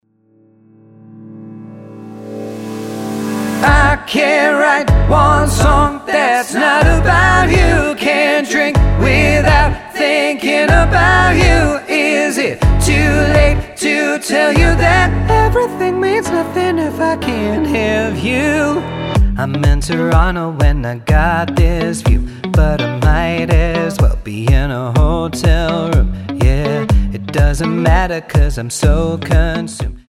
--> MP3 Demo abspielen...
Tonart:G Multifile (kein Sofortdownload.